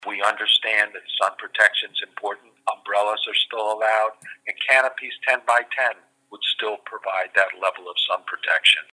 Regarding the need for sun-related protection, Mayor Meehan tells the Talk of Delmarva…